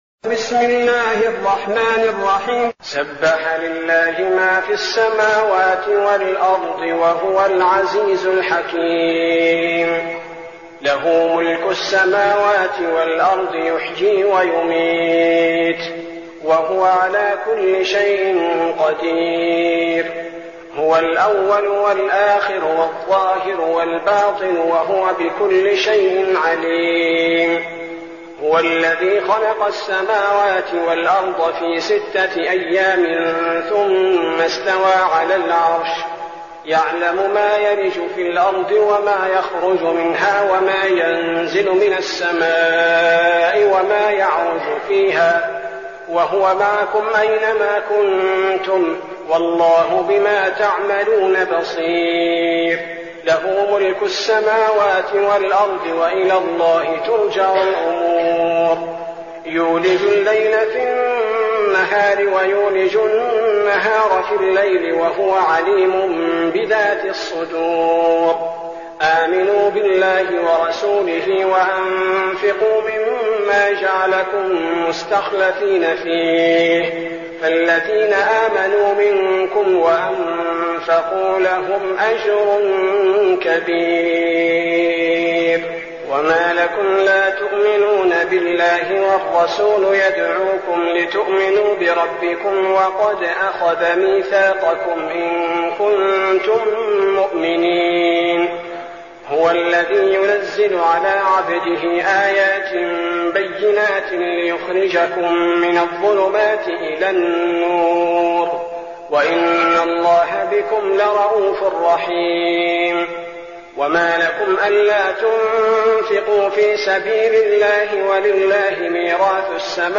المكان: المسجد النبوي الشيخ: فضيلة الشيخ عبدالباري الثبيتي فضيلة الشيخ عبدالباري الثبيتي الحديد